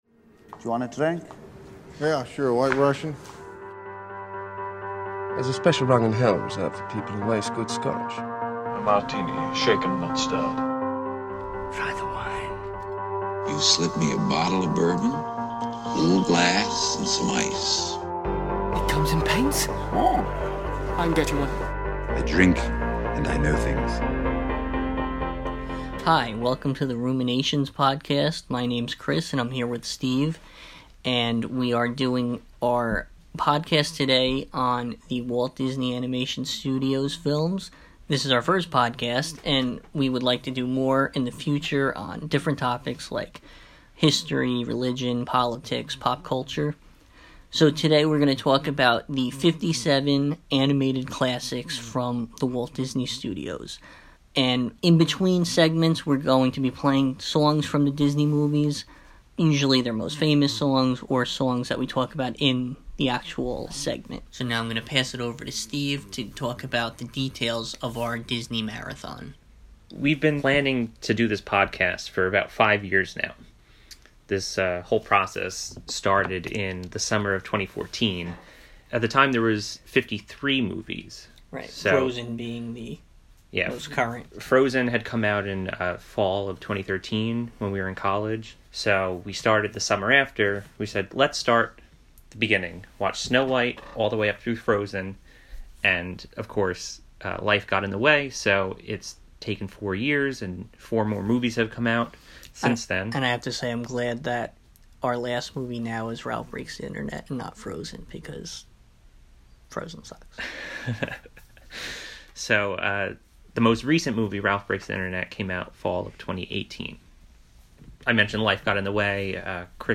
Welcome to the debut episode of Ruminations, a podcast on movies, politics, and religion, discussed over a glass—or three—of some type of adult beverage.